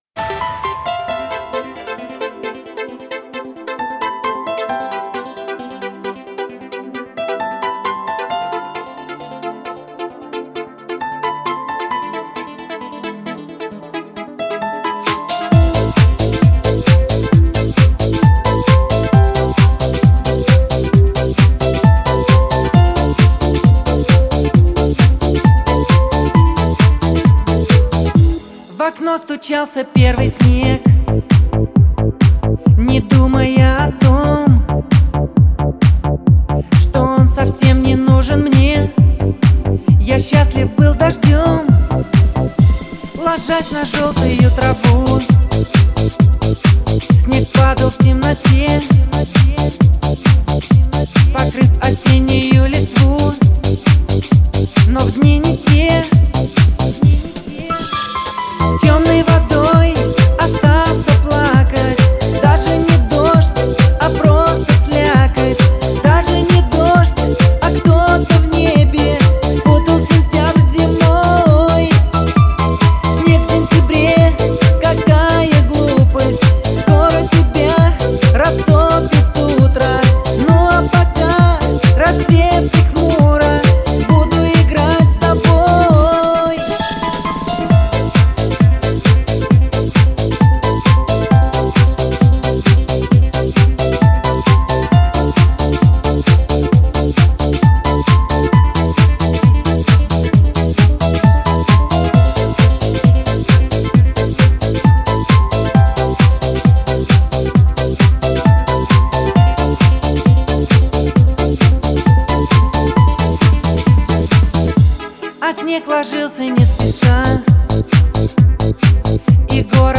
Категория: Русский Рэп/ Хип - Хоп